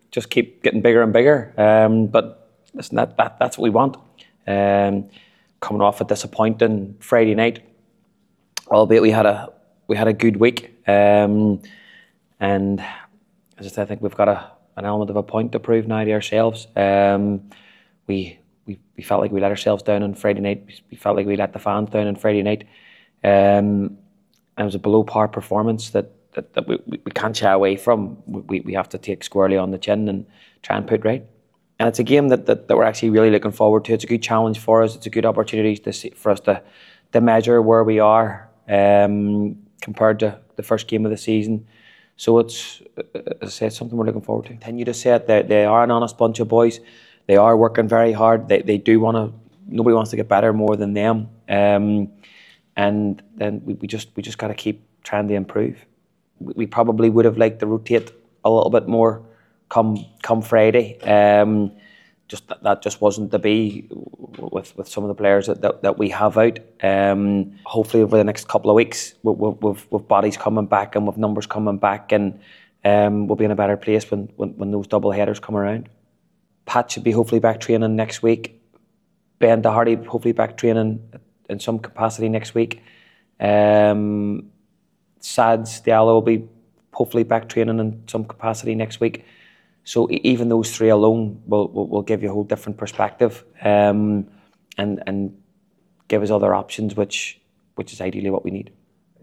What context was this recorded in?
has been speaking to the assembled media ahead of Friday’s visit of the champions